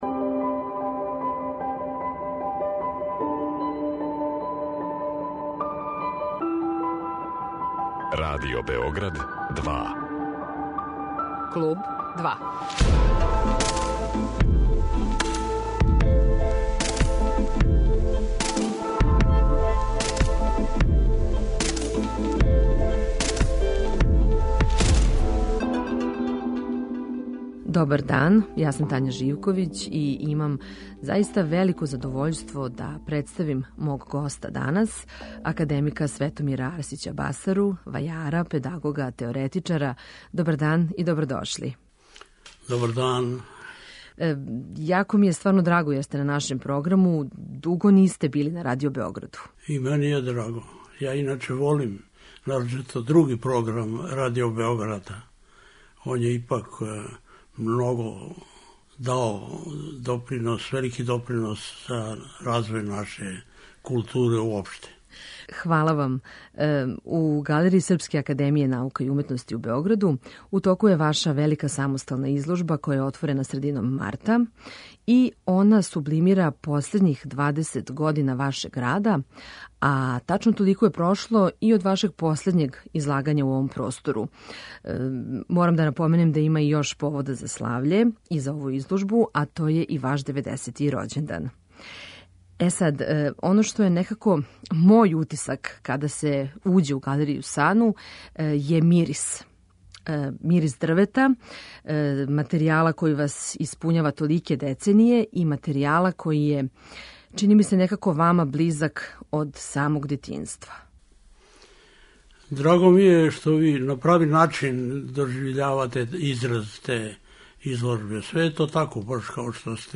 Гост 'Клуба 2' је академик Светомир Арсић Басара, вајар, педагог и ликовни теоретичар.